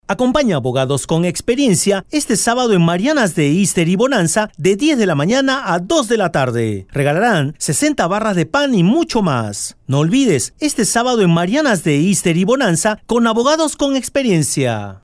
¡CÓMO SE ESCUCHA EN LA RADIO DE LAS VEGAS LA BUENA!